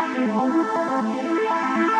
SaS_MovingPad03_120-A.wav